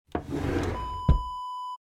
Desk drawer close sound effect .wav #1
Description: The sound of a desk drawer being closed
Properties: 48.000 kHz 16-bit Stereo
A beep sound is embedded in the audio preview file but it is not present in the high resolution downloadable wav file.
Keywords: desk, table, drawer, push, pushing, close, closing
drawer-desk-close-preview-1.mp3